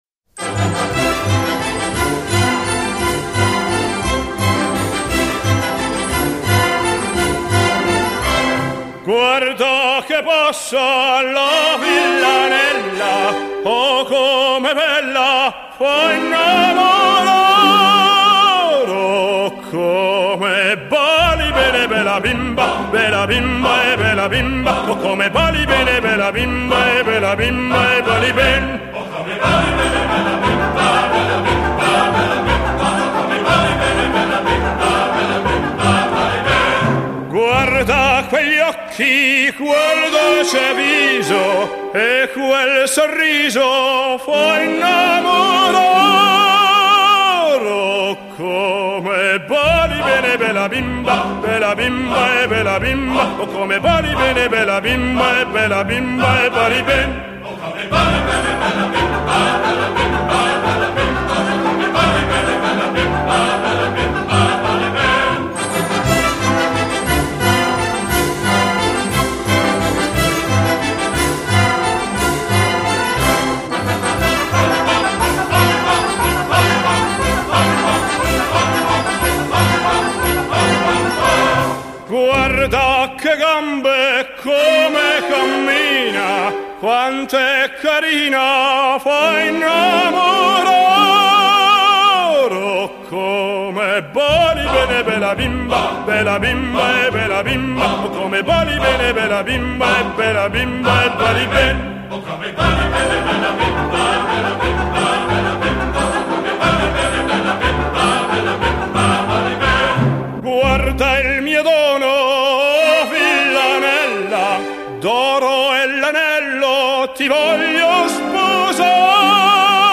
由于原录音是1962